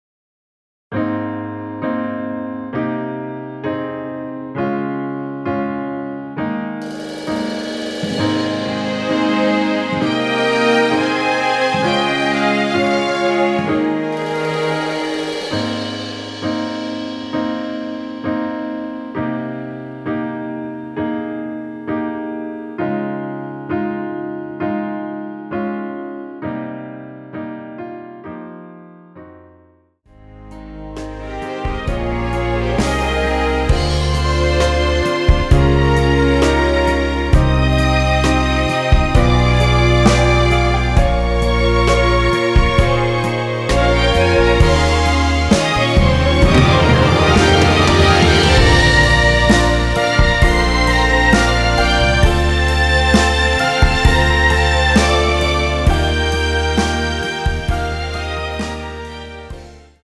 원키에서(+3) 올린 MR 입니다.(미리듣기 참조)
G#
앞부분30초, 뒷부분30초씩 편집해서 올려 드리고 있습니다.
중간에 음이 끈어지고 다시 나오는 이유는